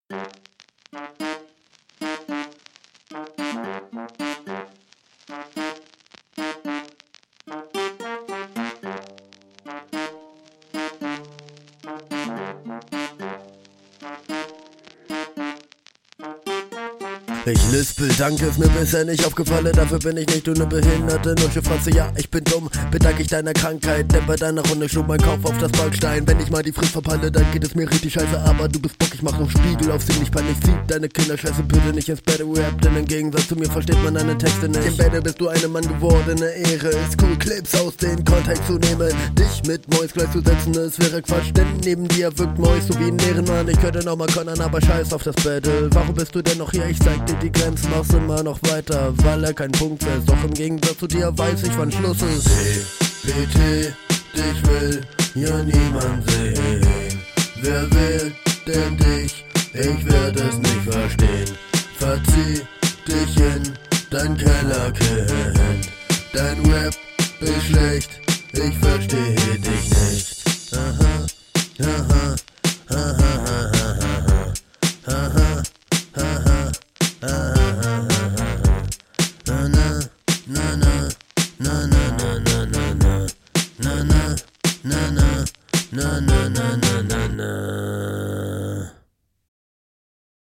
komisch dass du auf dem beat besser rüber kommst als auf deinem eigenen. alles in …